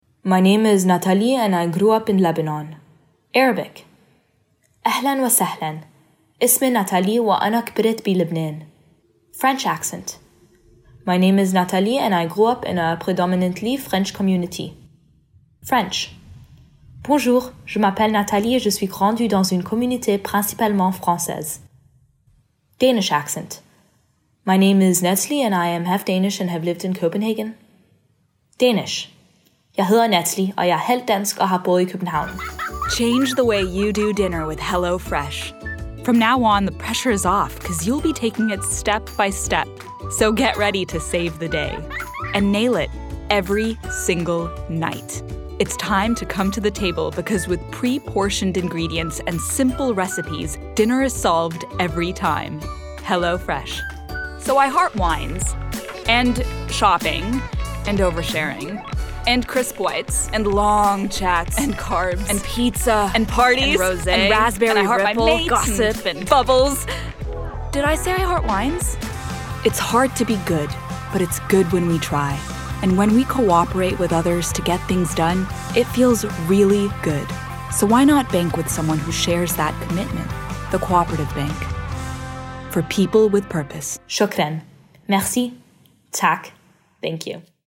Female / 20s, 30s / American, Arabic, Danish, English, French, Lebanese / American
Showreel